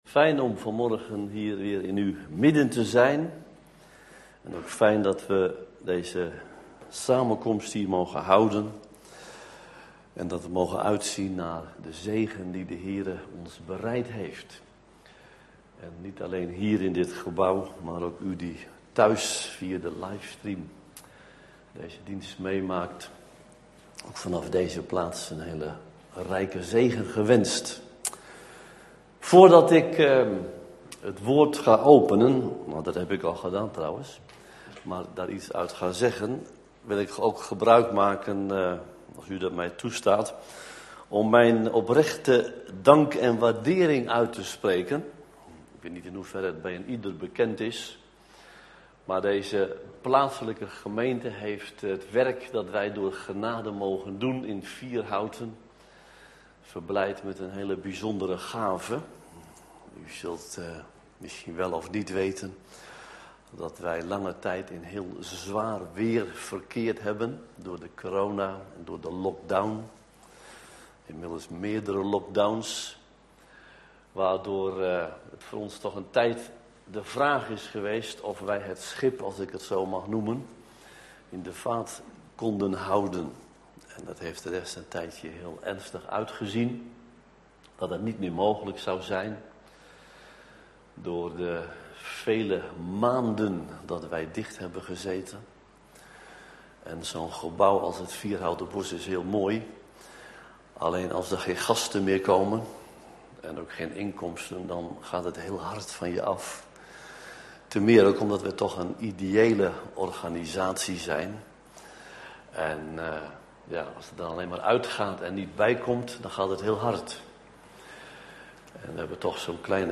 In de preek aangehaalde bijbelteksten (Statenvertaling)Psalmen 321 Een onderwijzing van David.